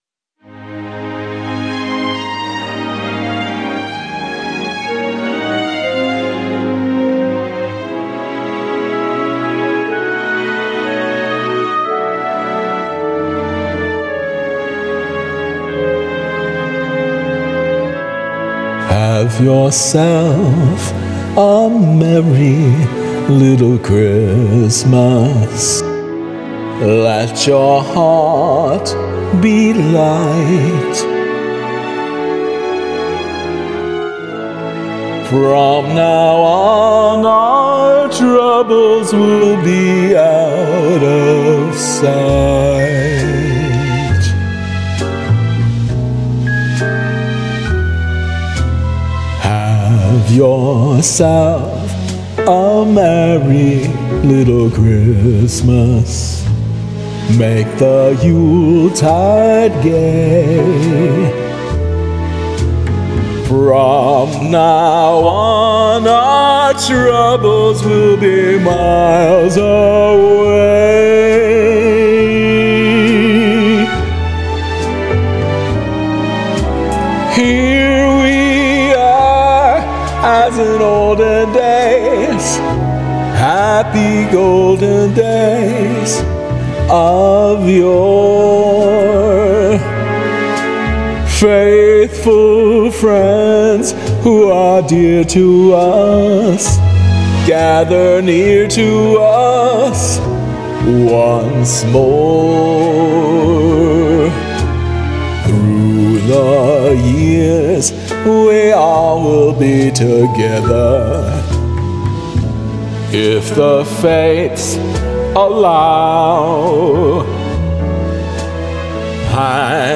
vocals
karaoke arrangement